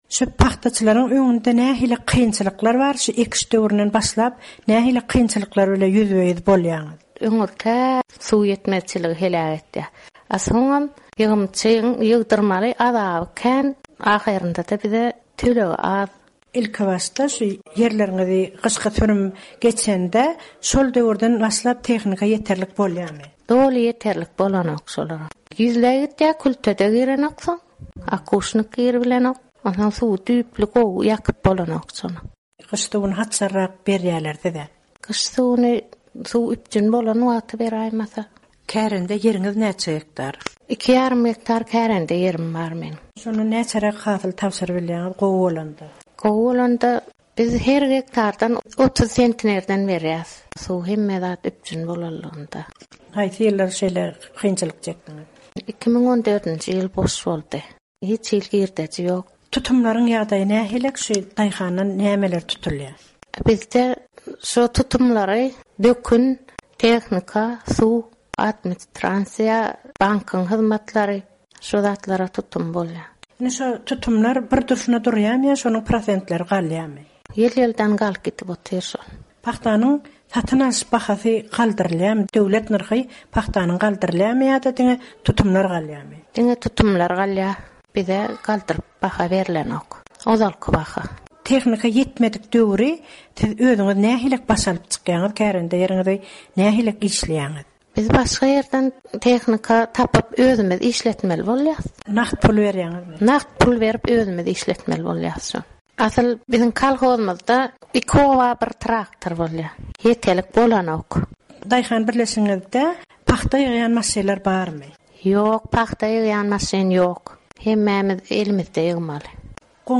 Emma Azatlyk radiosy bilen söhbetdeş bolýan kärendeçiler özlerine daýhançylykdan gazanç etmegiň ýyl-ýyldan kynlaşýandygyny aýdýarlar.